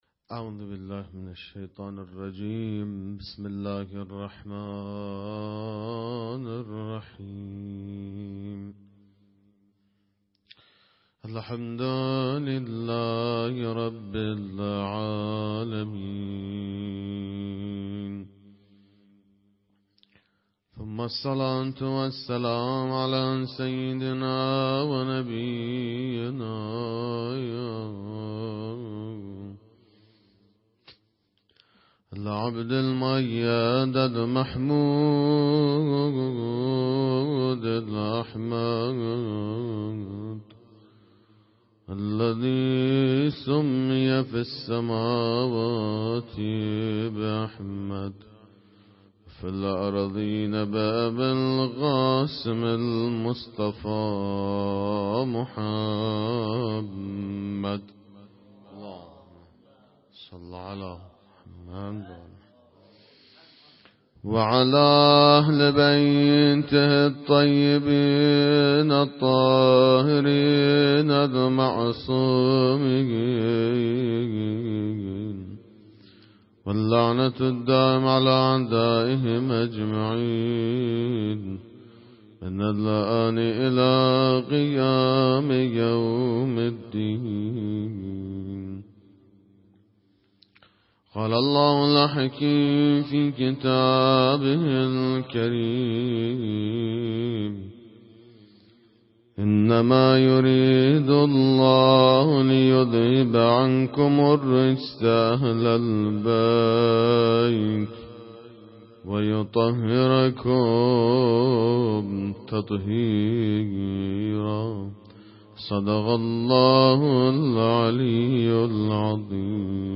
مجموعه صوتی مراسم هفتگی 6 دی ماه 97
سخنرانی